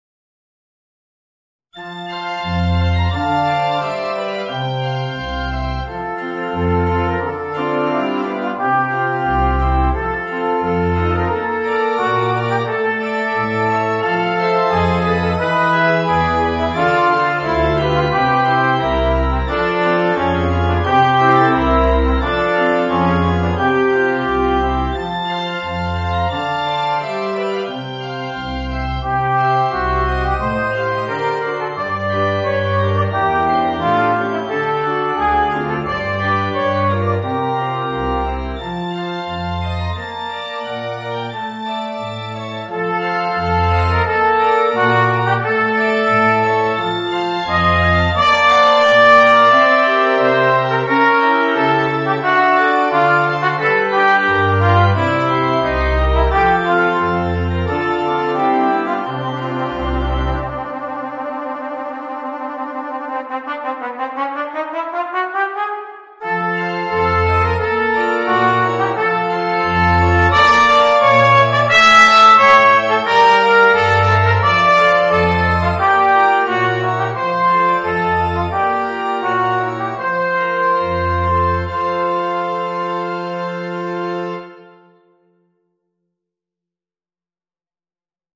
Voicing: Trumpet and Organ